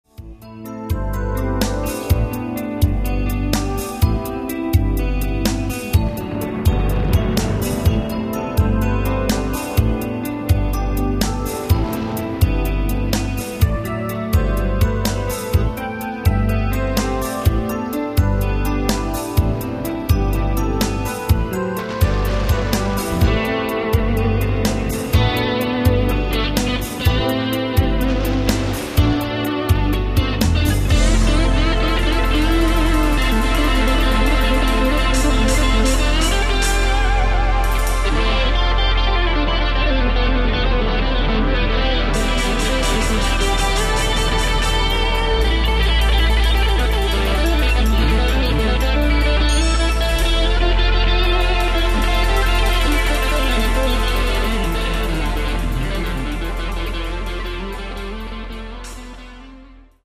ein reines Rock-Album